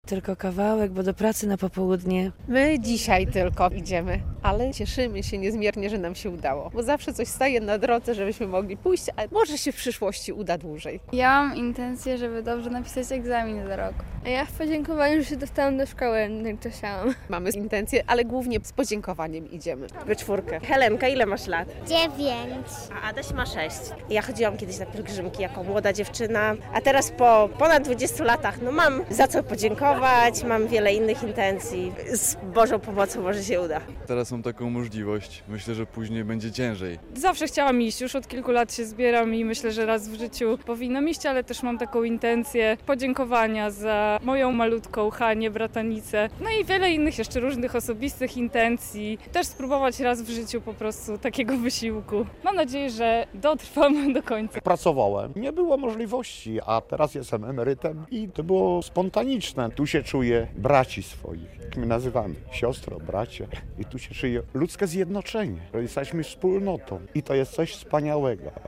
Wyruszyła 41. Piesza Pielgrzymka Łomżyńska na Jasną Górę - relacja